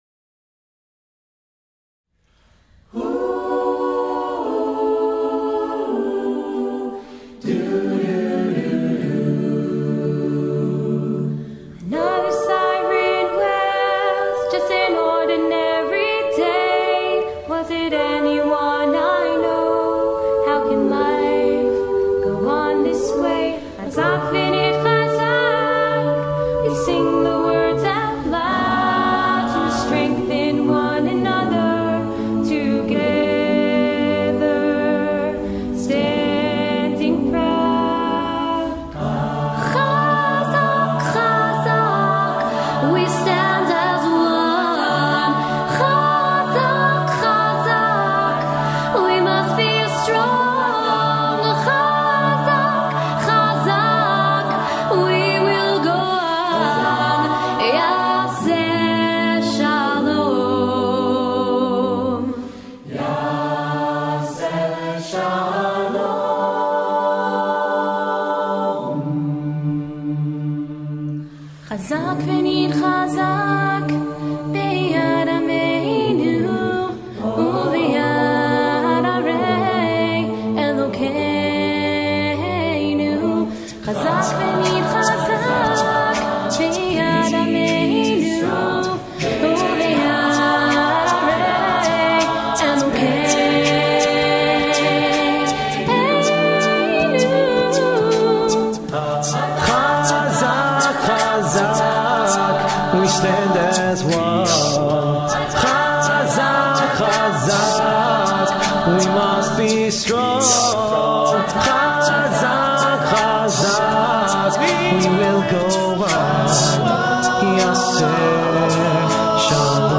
SATB, Soloists, band
Genre: Pop
Contains solos: Yes